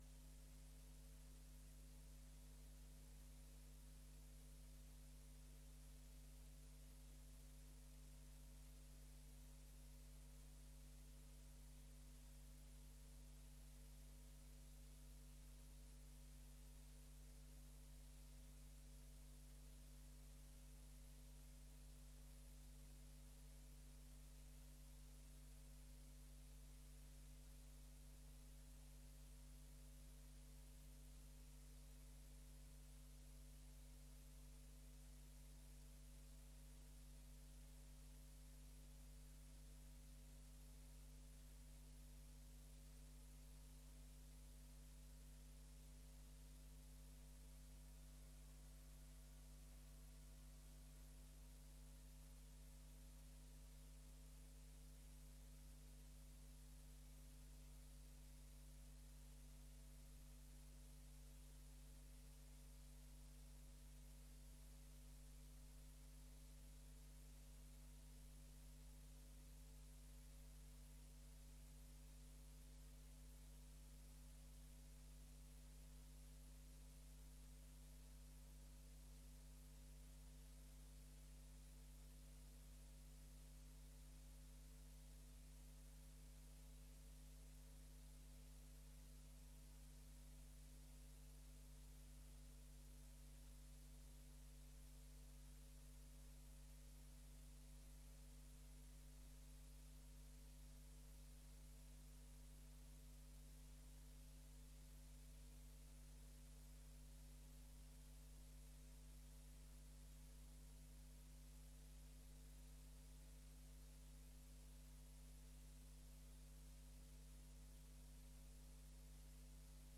Raadsleden worden in de gelegenheid gesteld vragen te stellen aan de begeleidingsgroep en monitorgroep.
Locatie: Raadzaal
Opening door burgemeester F. Binnendijk